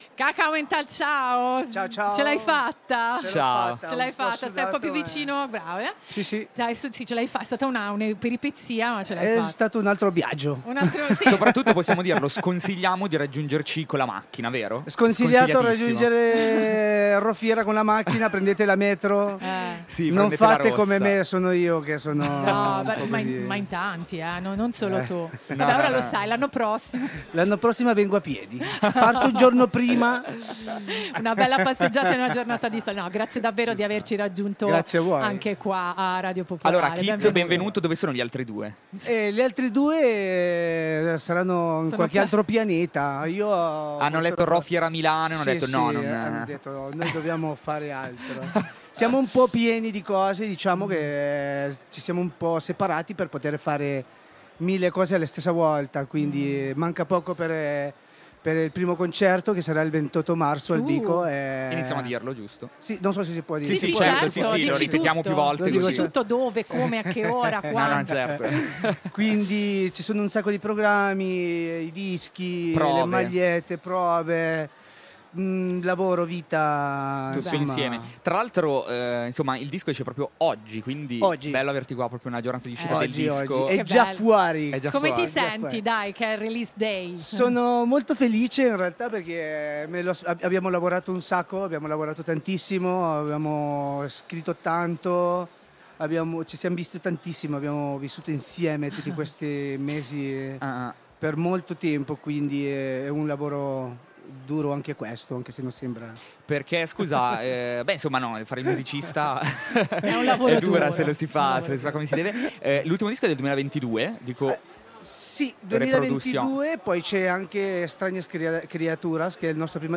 (oggi in diretta da ‘Fa La Cosa Giusta’ a Rho Fiera Milano)
L’intervista